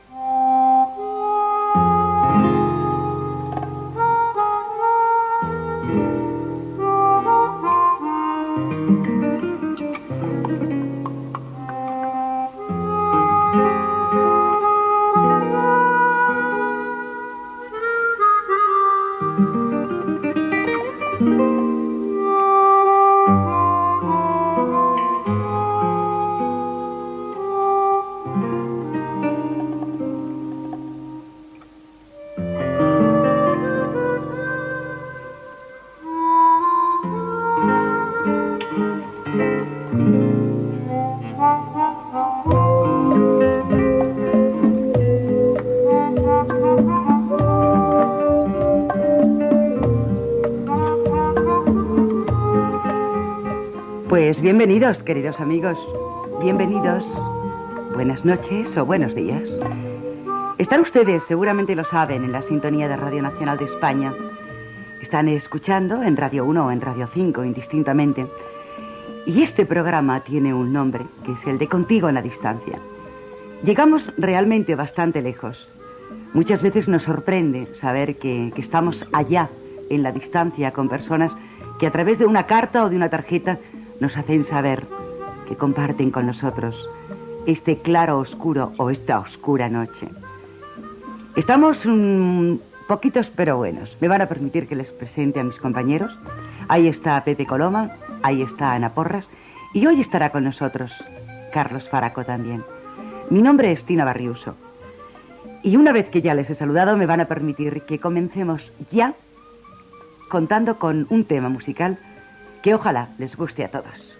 Sintonia del programa, presentació, equip, tema musical.
Musical